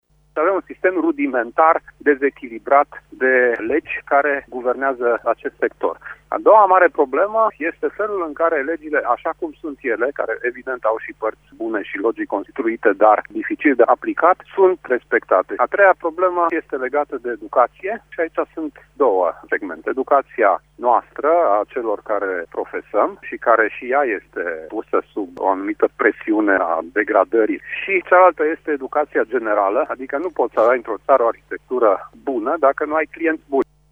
Ordinul Arhitecţilor din România invită la dialog instituţiile statului şi asociaţiile profesionale pentru a remedia şi amenda erorile legislative şi procedurale din domeniul construcţiilor. Invitat în rubrica Apel Matinal, la Radio România Actualităţi